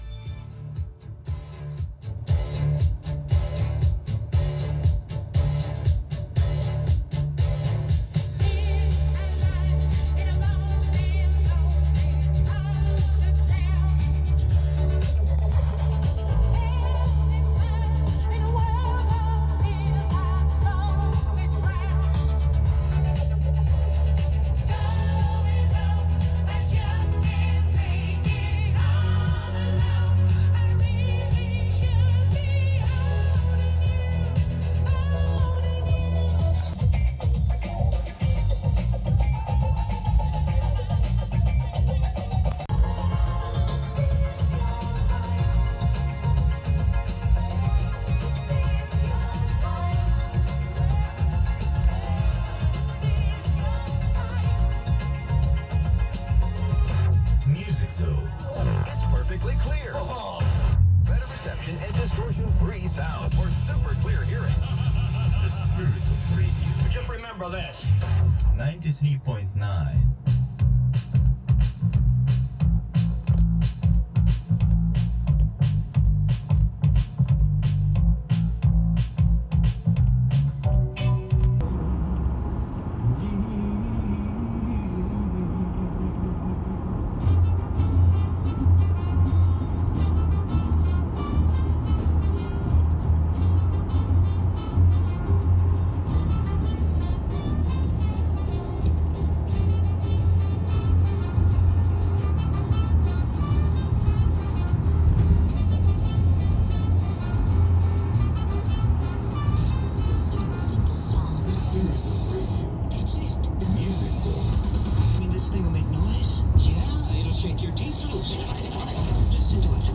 The first part the reception is good bc I was in the driveway but for the second part we were on the road. The reception finally gave out at 427/QEW. During the first part around 4 PM it was 70's/80's and later after 630 it was all 50's, 60's and 70's oldies, You will hear two station ID's. During the oldies segment I heard "I told Every Little Star" by Linda Scott which surprised me and which would be a turn off to to the 80's audience.
The IDS sound very prefetionaly made for a pirat station.
94.9-Oakville.mp3